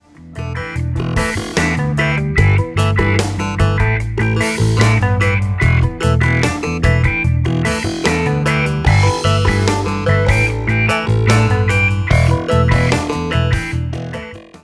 1 – Full Version With Guide Drum Track